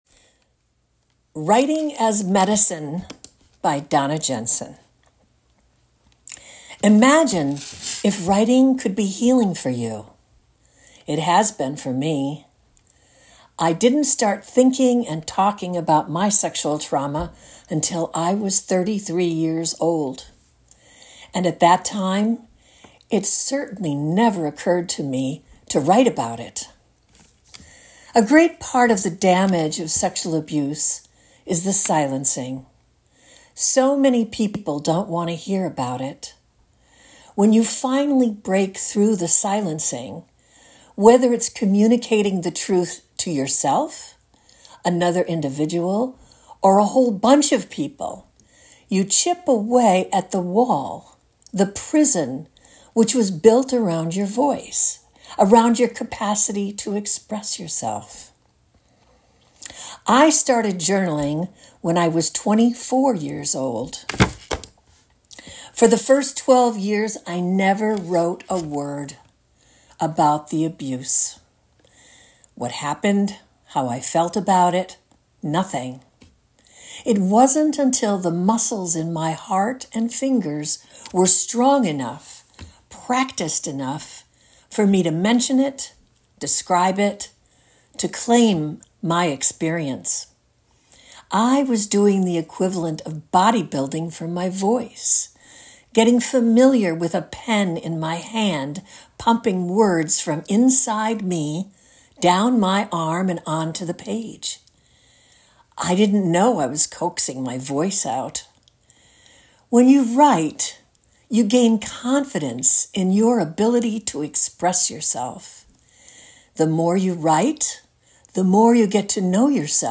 Listen to this story read by the author